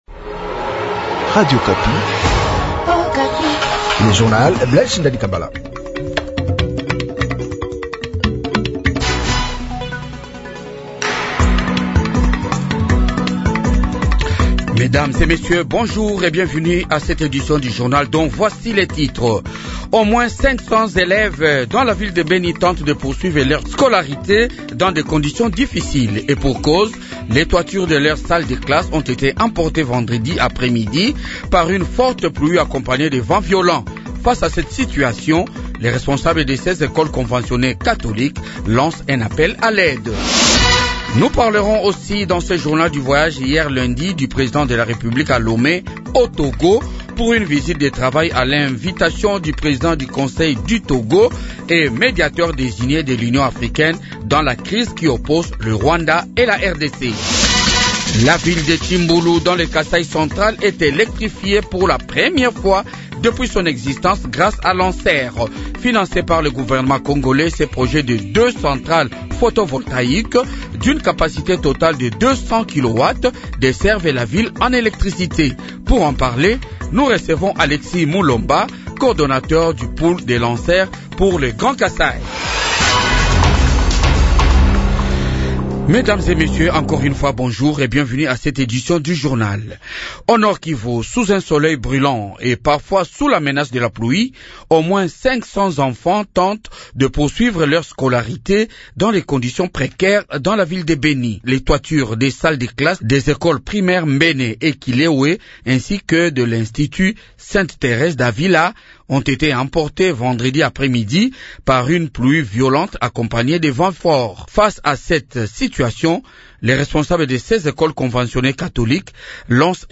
Journal du matin 6h